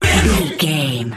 Aeolian/Minor
E♭
synthesiser
Eurodance